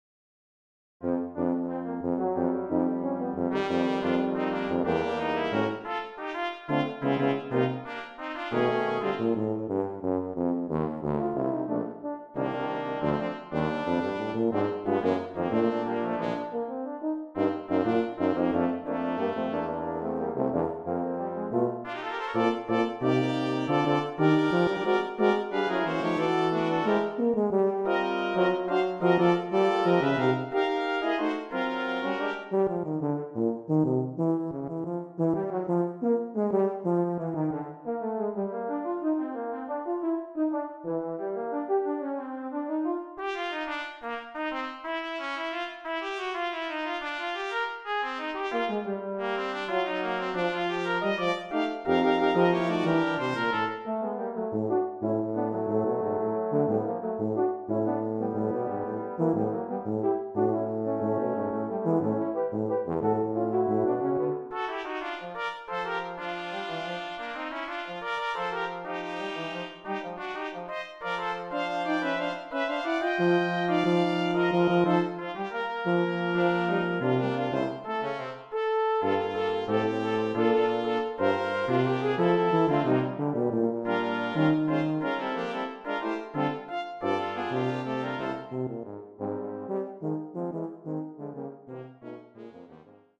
Voicing: Brass Quintet